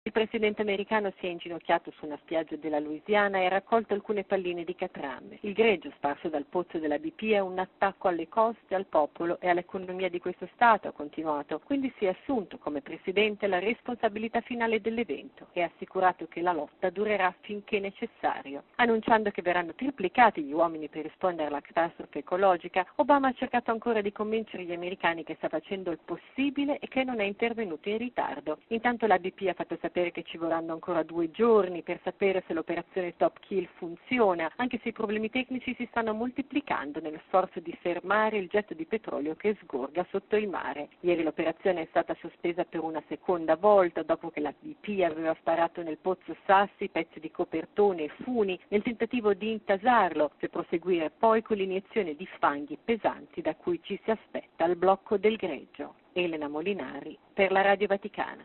“Non vi abbandoneremo” – ha detto alle popolazioni dei 5 Stati colpiti dal disastro ambientale.